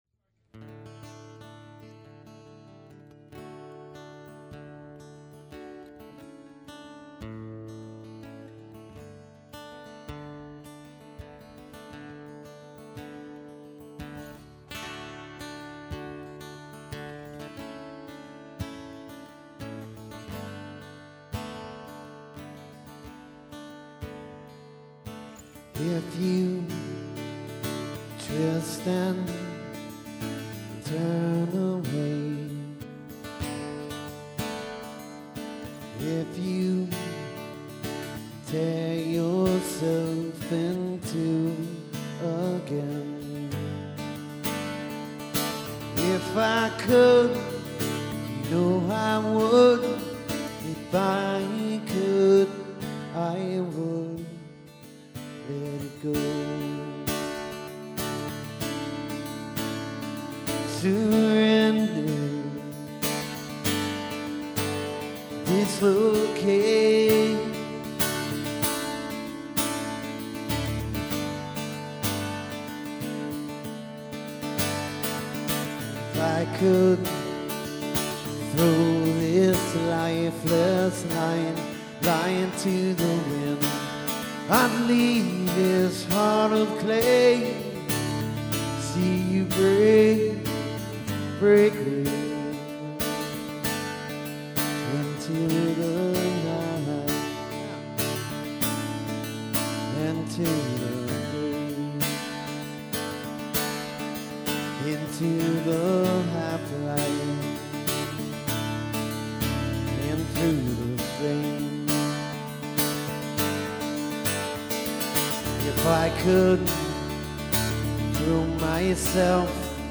Recorded live at the Coffee Connection